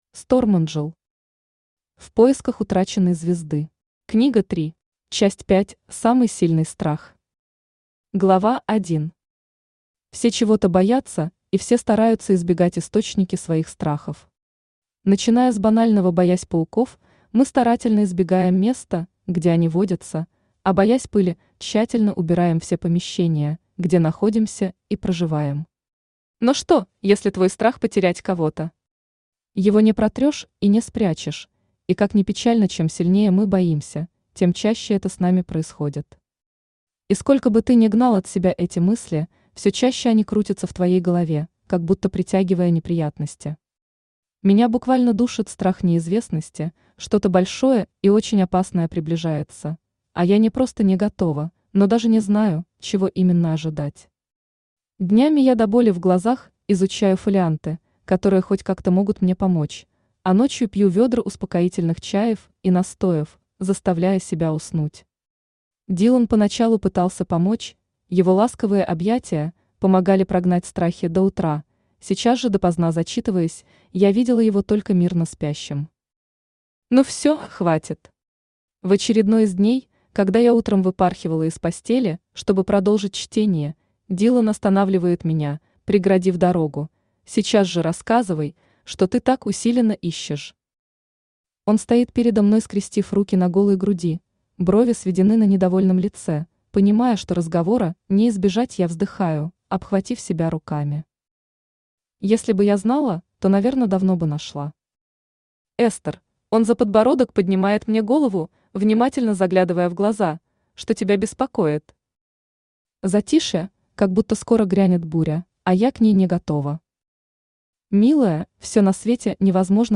Аудиокнига В поисках утраченной звезды. Книга 3 | Библиотека аудиокниг
Читает аудиокнигу Авточтец ЛитРес.